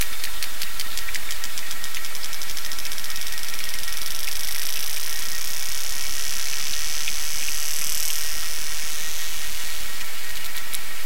На этой странице собраны их уникальные вокализации: от низкочастотных стонов до резких щелчков эхолокации.
Возвращаясь к добыче звук разносится на 6 км